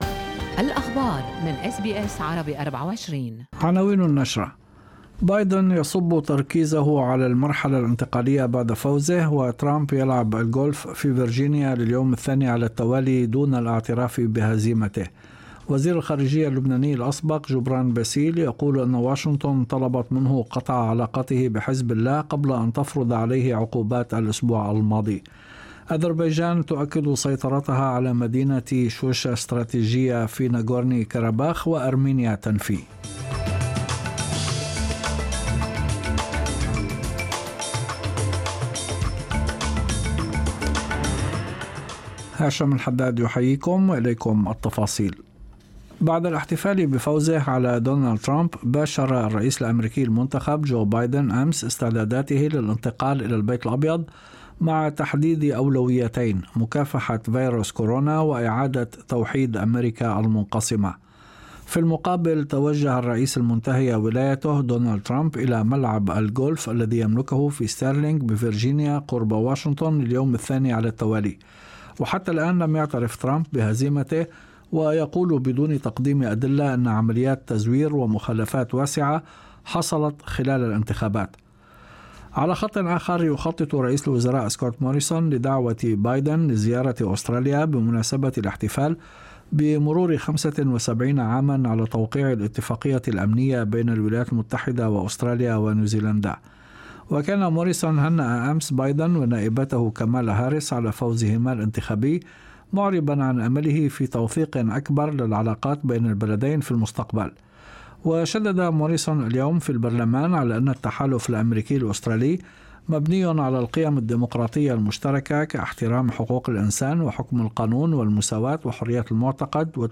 نشرة أخبار المساء 9/11/2020